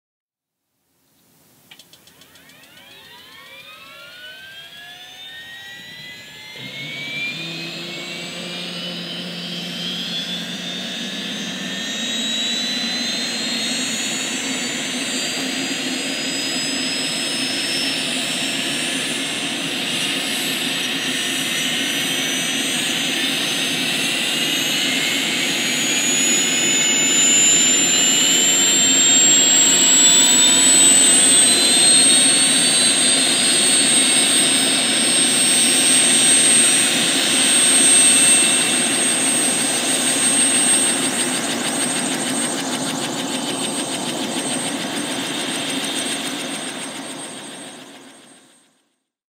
startup_exterior_mono_raw.wav